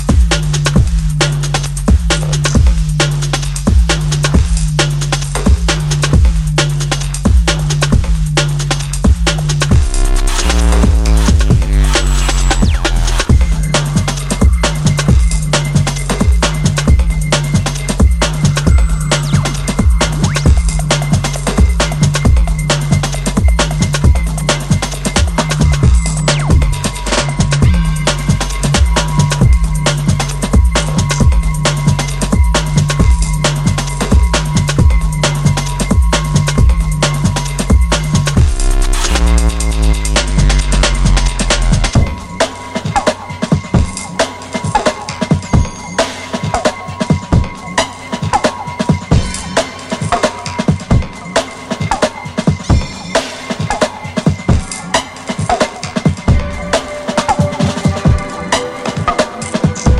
腹を抉る重低音のアタックが効いたシンプルなアーメン使いが渋いジャングリッシュチューン